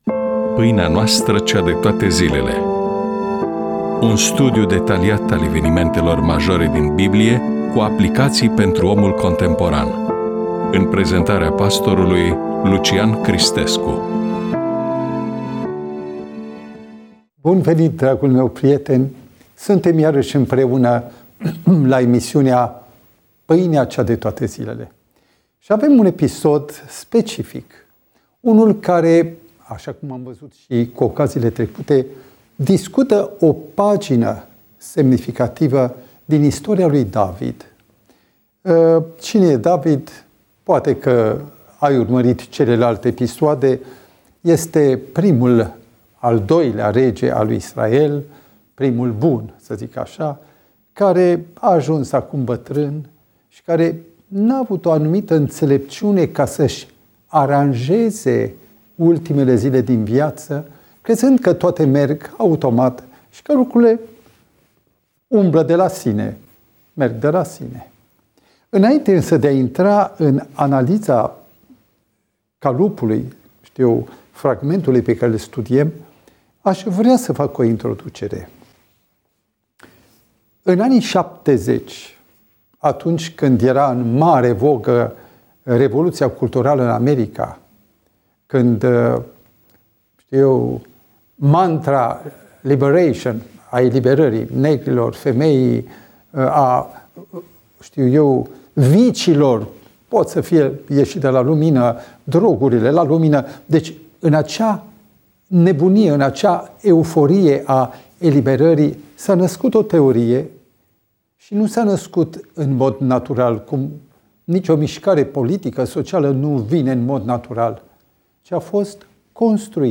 EMISIUNEA: Predică DATA INREGISTRARII: 27.02.2026 VIZUALIZARI: 9